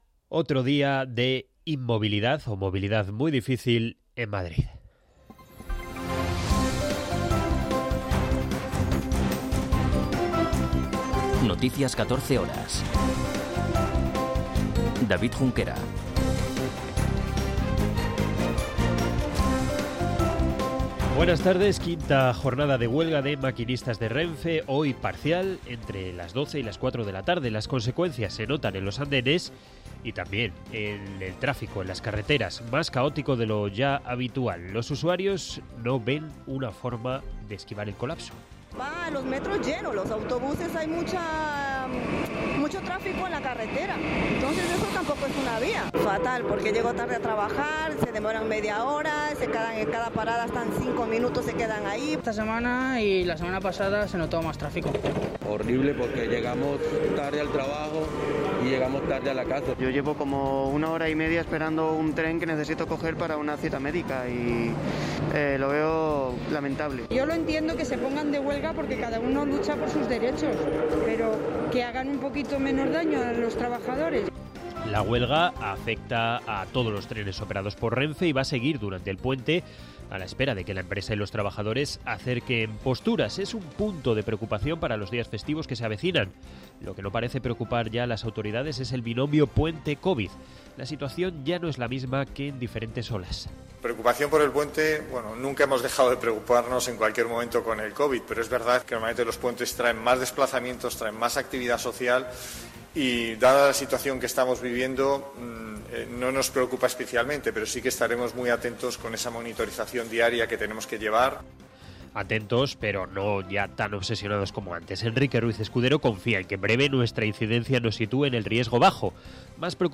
Noticias 14 horas 07.10.2021